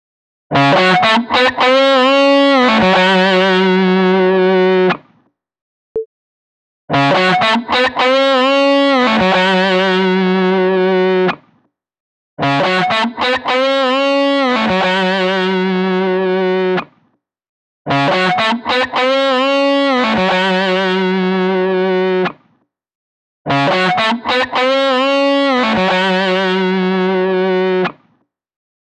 Stereo Touch [ pseudo-stereo ] …
Original then presets : “guitar-space”, “guitar-cabinet”, “surround-delay”, “narrow-space”.